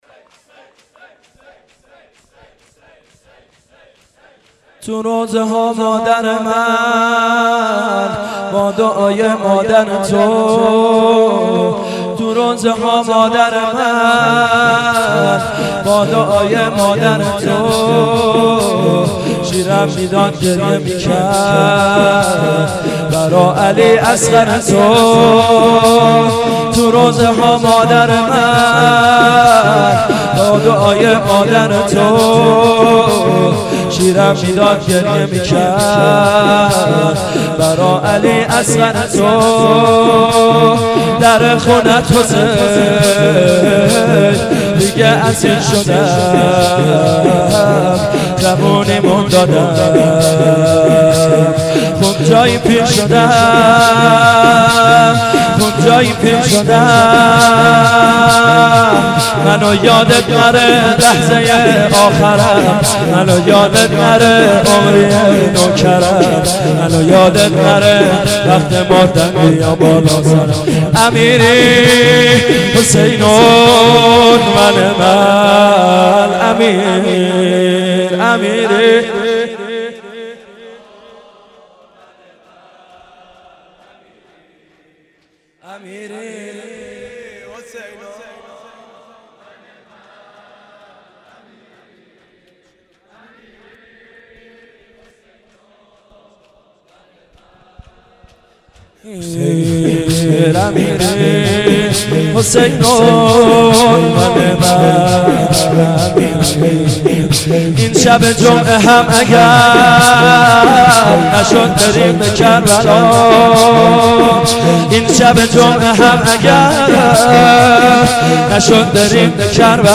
shur